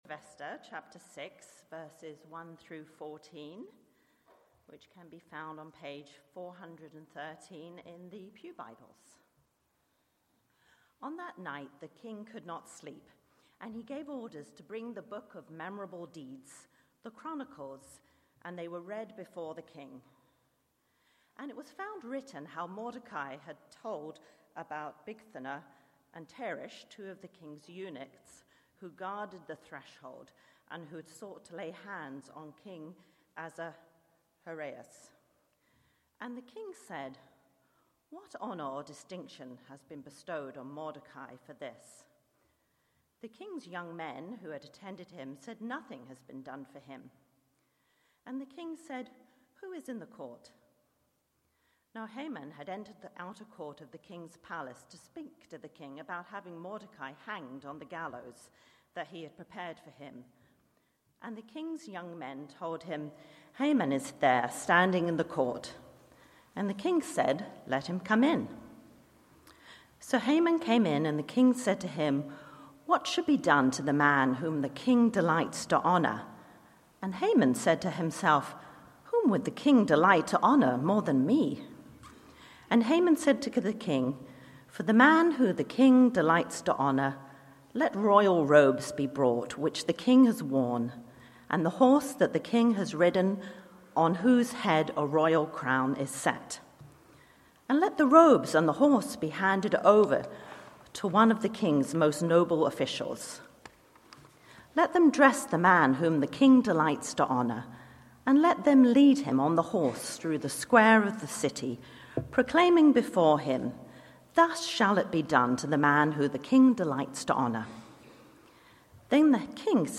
Passage: Esther 6:1-14 Sermon